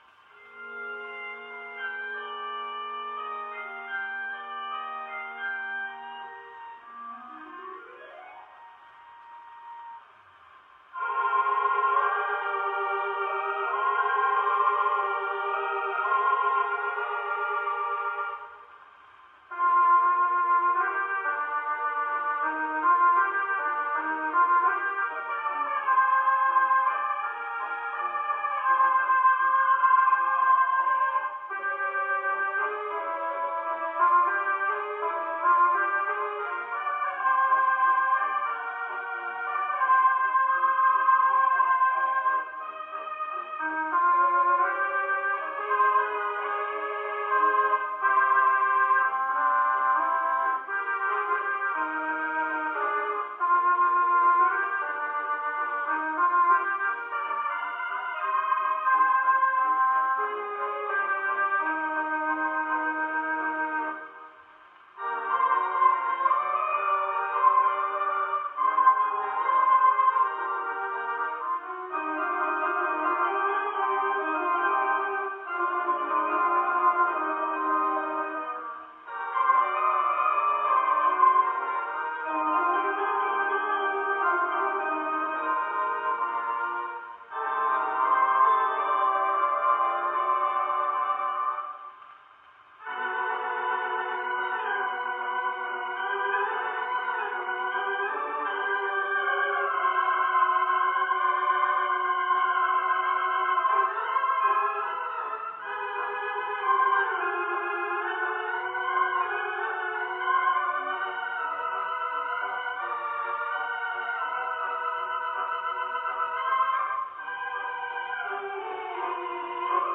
Played on the Morton Organ, Loew's Valencia Theatre